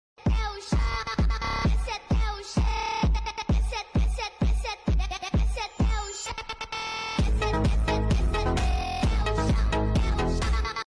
ضحكة😂😂😭 Sound Effects Free Download